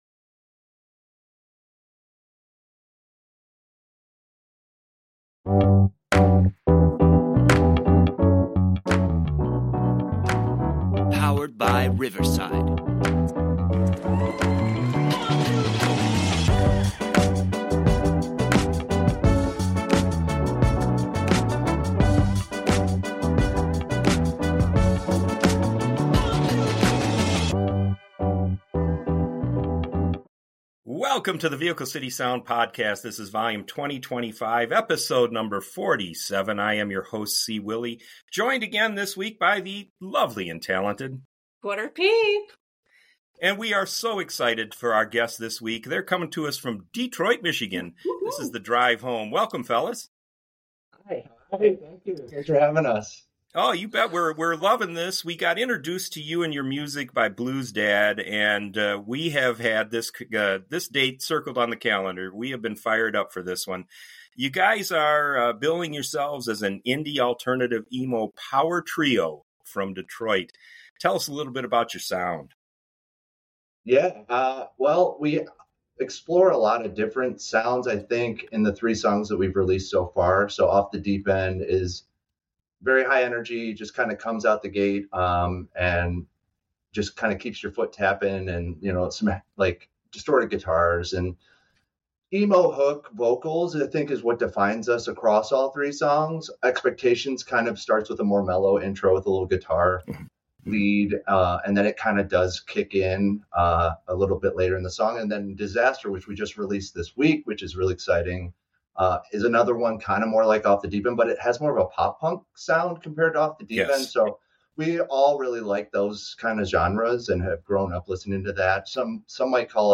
high-energy
This trio will definitely win you over!